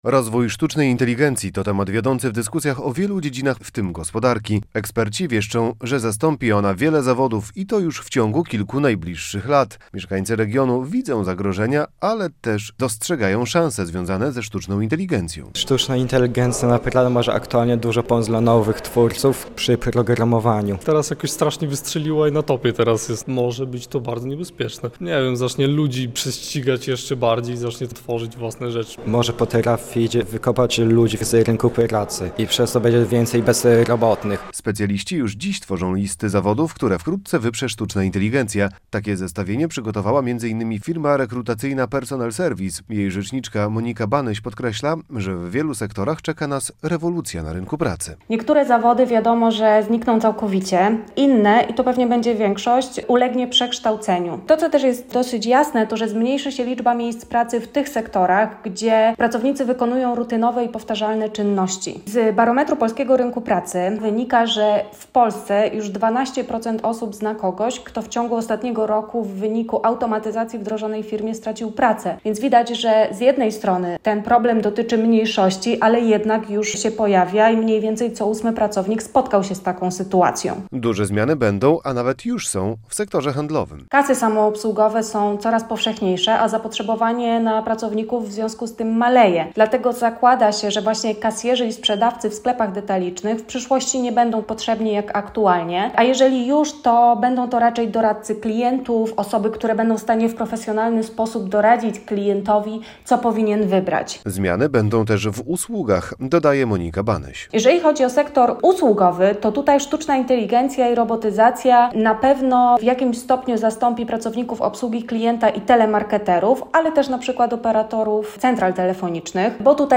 Wokół portfela - Sztuczna inteligencja - magazyn | Pobierz plik.